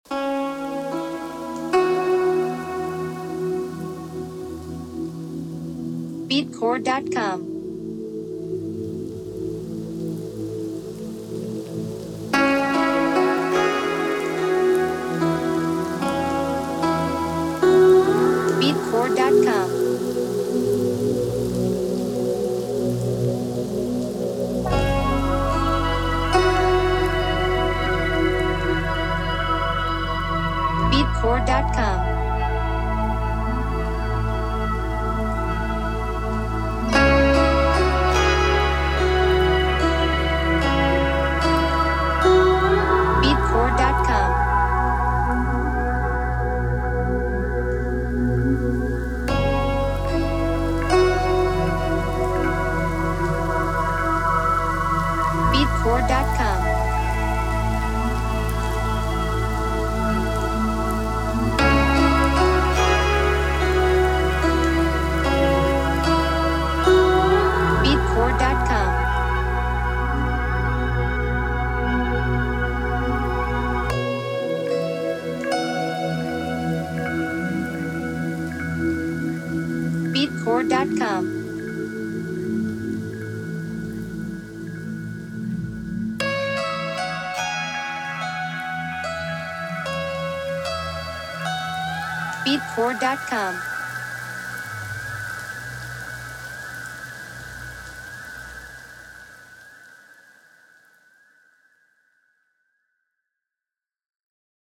Genre: Ambient Easy Listening Mood: Meditative Therapy
Time Signature: 4/4
Instruments: Synthesizer